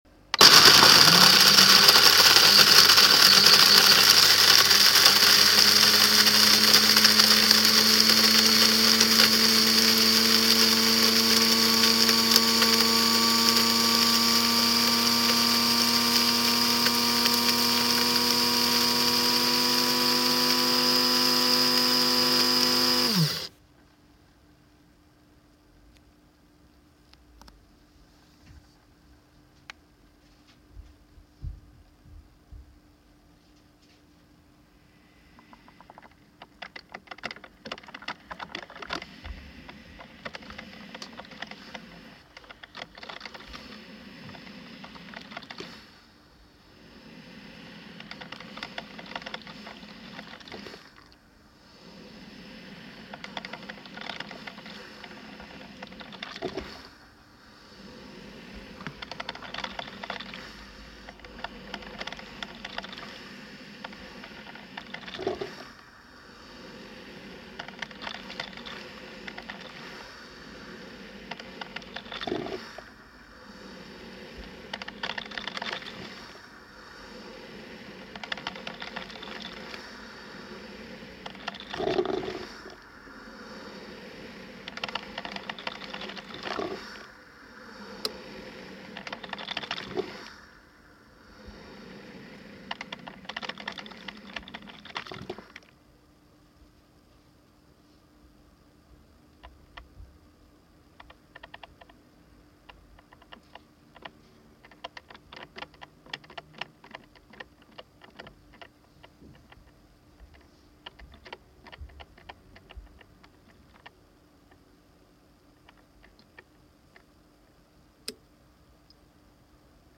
一応参考のために実際にSC-A211を使ってコーヒーを作っているところを録音したデータを載せます。
聞いてもらうと分かりますが、最初にコーヒー豆をミルする作業で大きい音がするんですよね。
siroca-sca211-review-sound.mp3